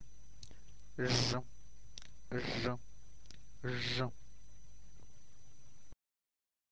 Æ_æ - letter like English s in word measure.